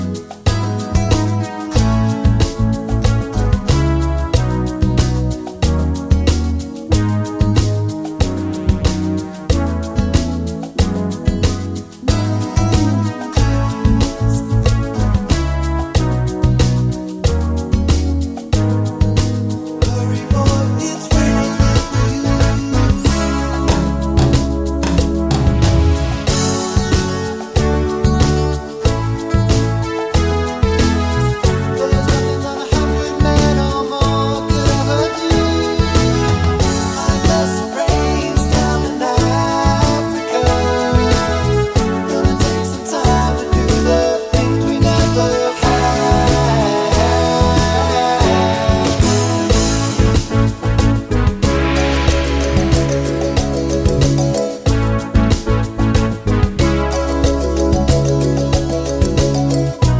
Twofers Medley Soft Rock 4:44 Buy £1.50